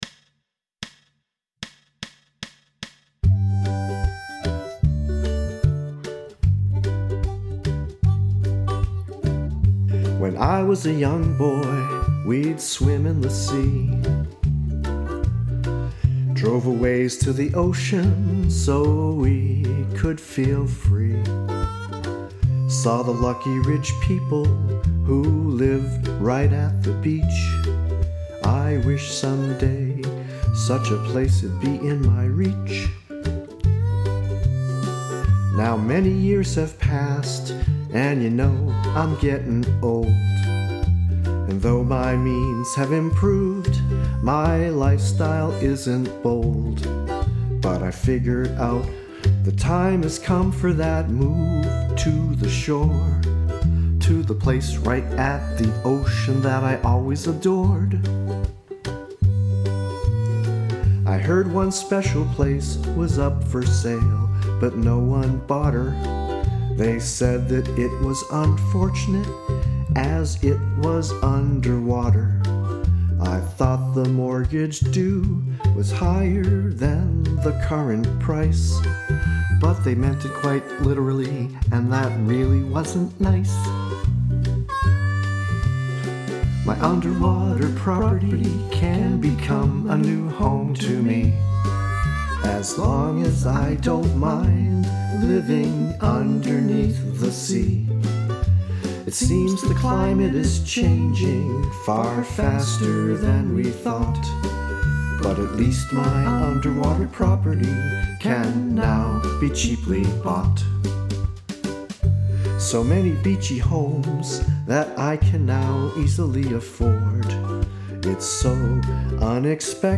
demo recording.